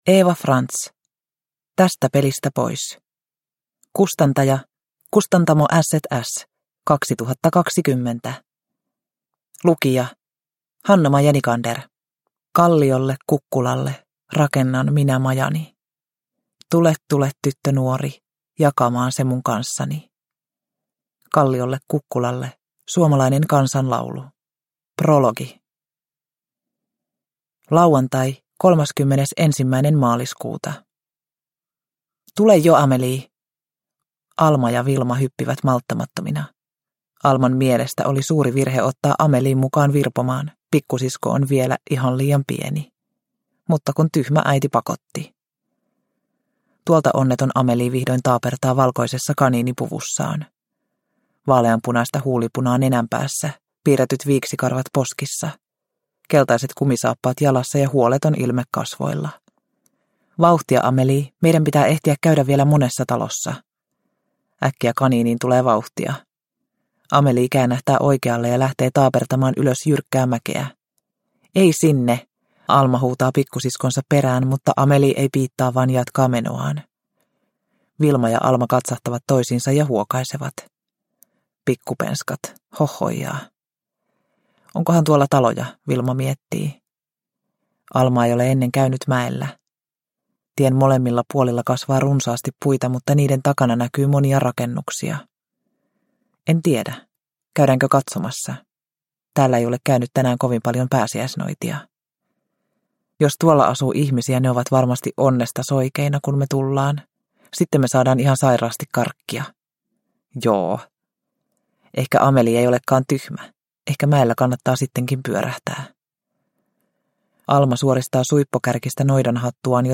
Tästä pelistä pois – Ljudbok – Laddas ner